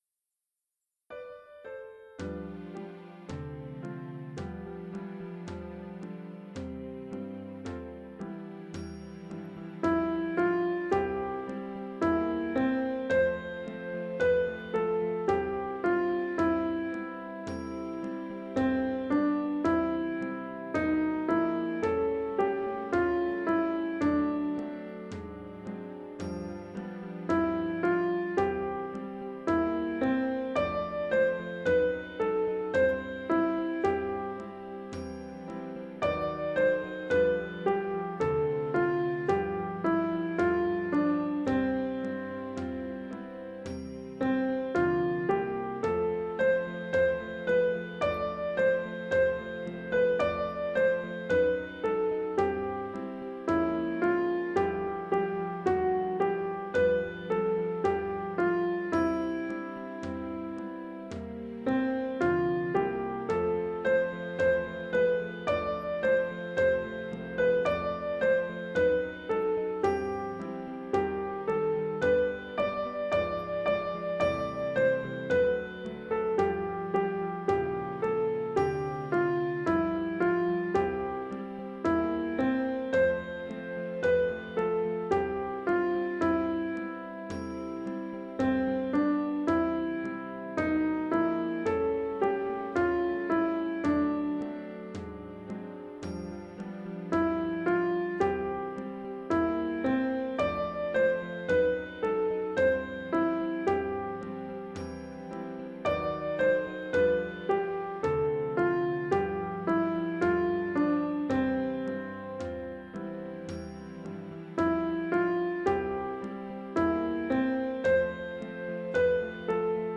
rehearsal recording